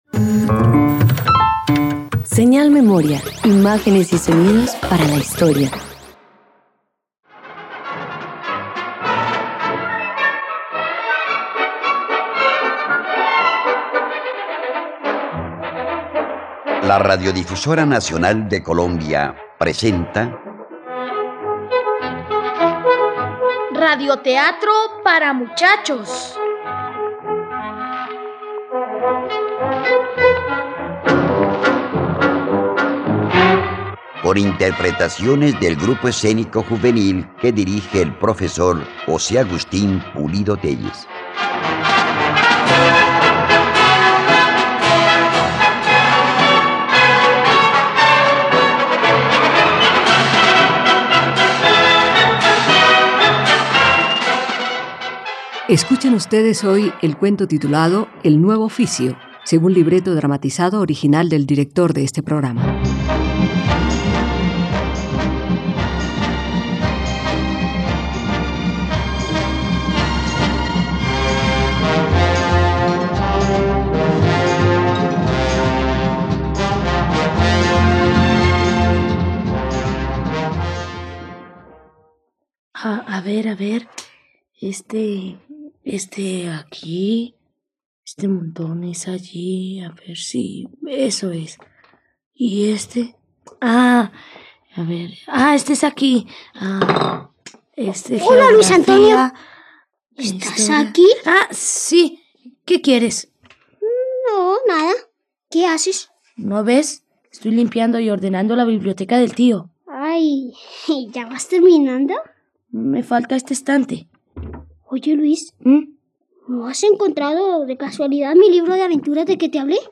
El nuevo oficio - Radioteatro dominical | RTVCPlay